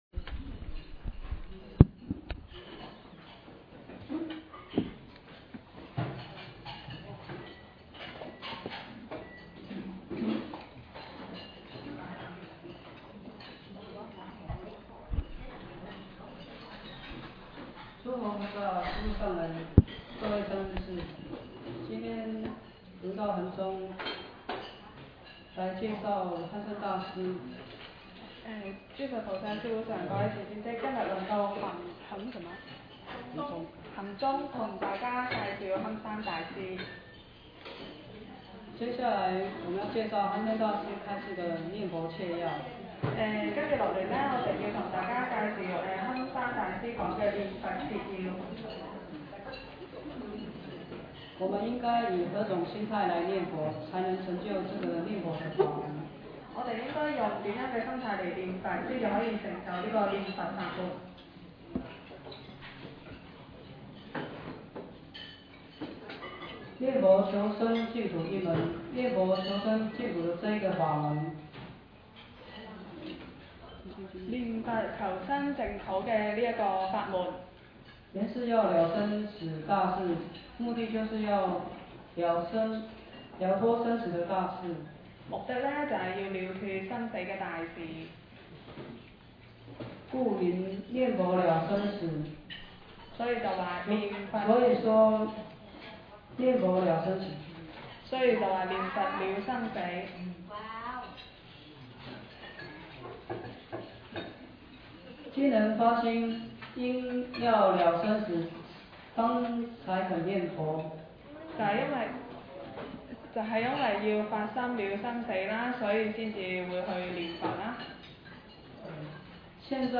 2016 年講述於溫哥華金佛寺